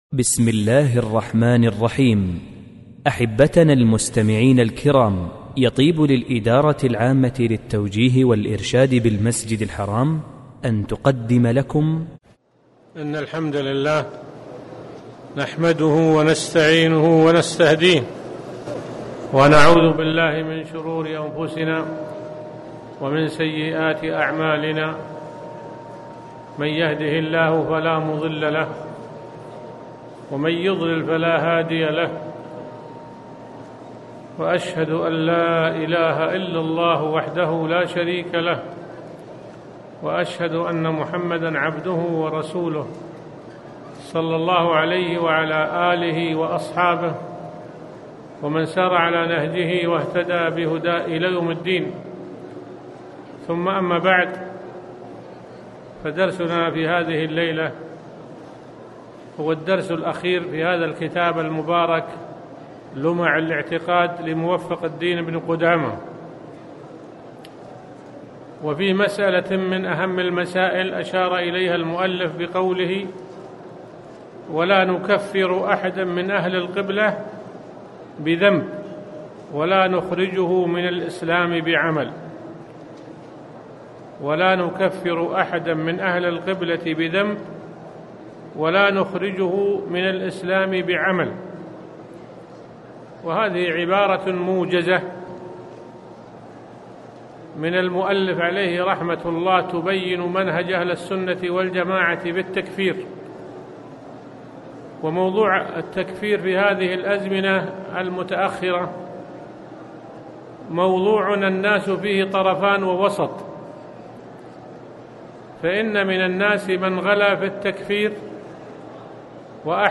تاريخ النشر ١٩ صفر ١٤٣٩ المكان: المسجد الحرام الشيخ